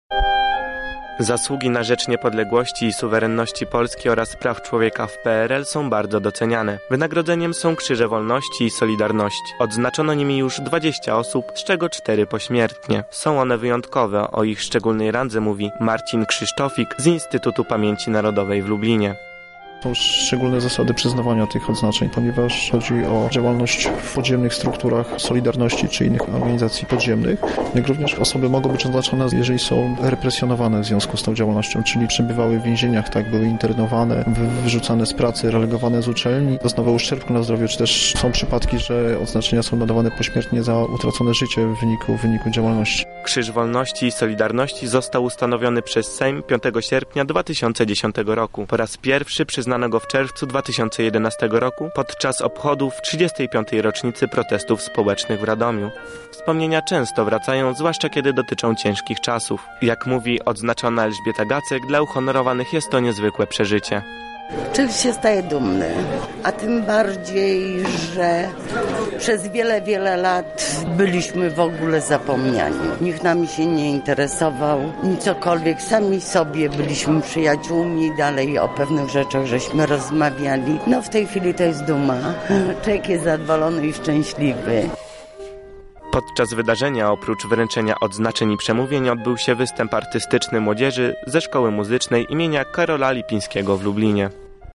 W lubelskim Instytucie Pamięci Narodowej odbyło się wręczenie odznaczeń Państwowych RP nadał odznaczenia działaczom opozycji antykomunistycznej. Zebrani otrzymali nagrody za zasługi na rzecz niepodległości i suwerenności.